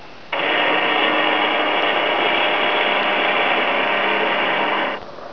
flaps.wav